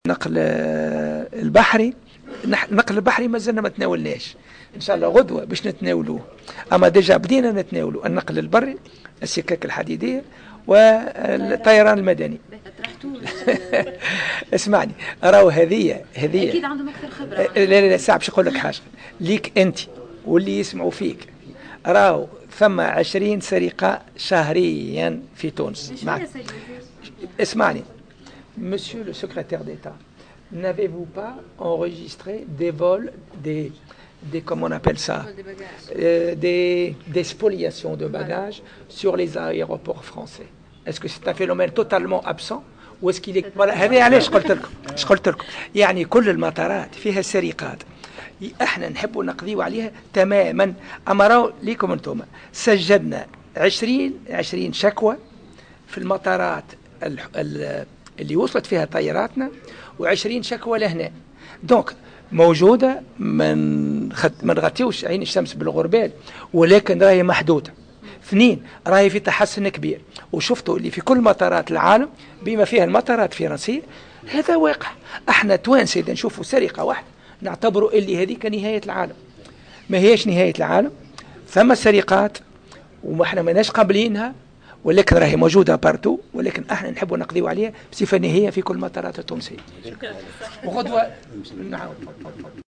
أكد وزير النقل محمود بن رمضان في تصريح للجوهرة "اف ام" على هامش استقباله كاتب الدولة الفرنسي المكلف بالنقل اليوم الأربعاء تسجيل 20 سرقة شهريا في المطارات التونسية لأمتعة المسافرين .